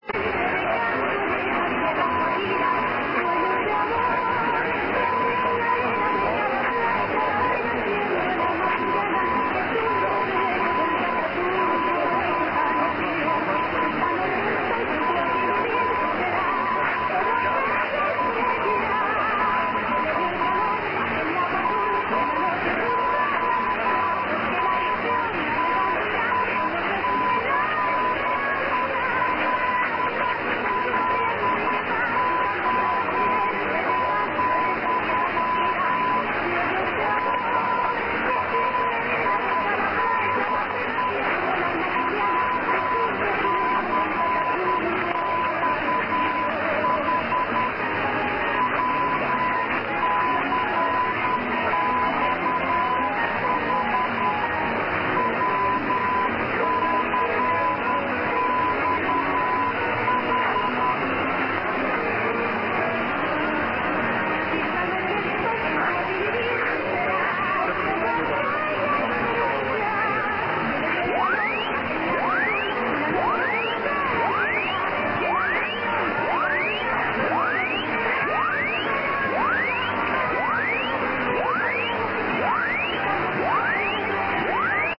>It sounds very close to 1 kHz to me, and maybe 10 wpm or so.  There
>are also some sweeps at the end of the clip.